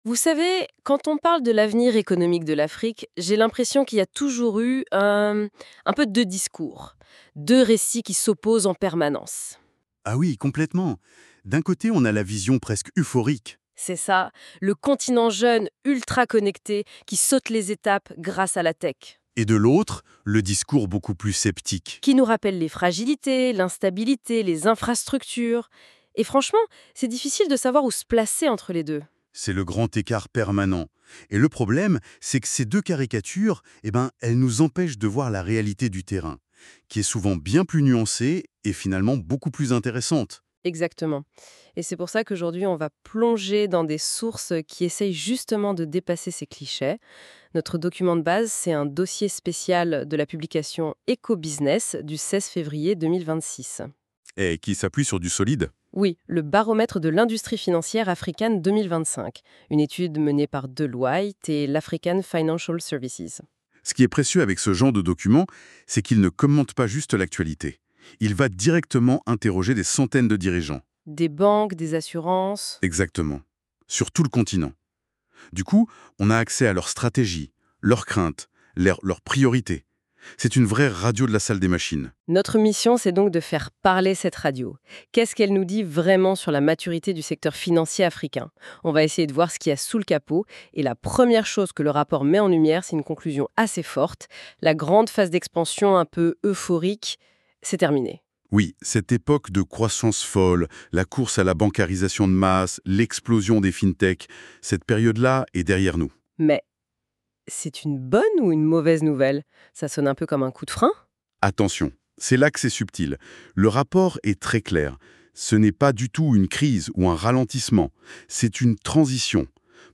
Podcast - débat Eco Busniness 16 fév 2026.mp3 (13.39 Mo)